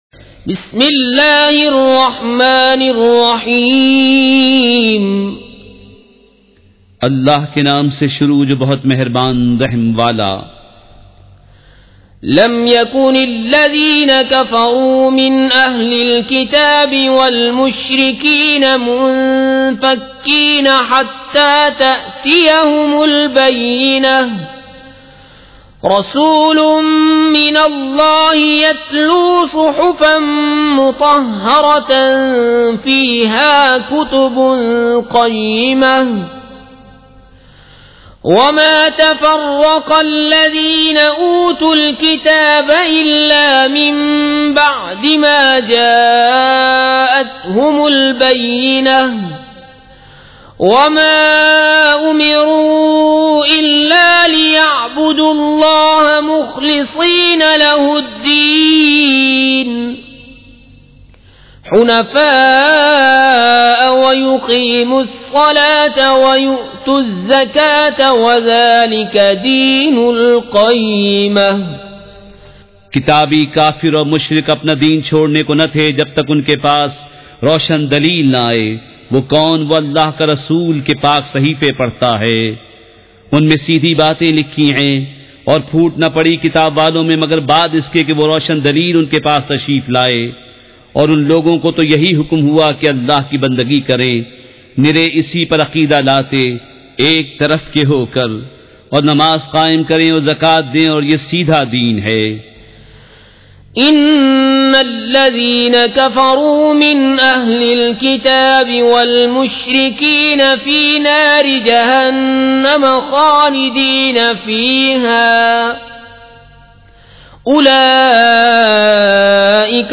سورۃ البینۃ مع ترجمہ کنزالایمان ZiaeTaiba Audio میڈیا کی معلومات نام سورۃ البینۃ مع ترجمہ کنزالایمان موضوع تلاوت آواز دیگر زبان عربی کل نتائج 1912 قسم آڈیو آڈیو ڈاؤن لوڈ MP 3 ڈاؤن لوڈ MP 4 متعلقہ تجویزوآراء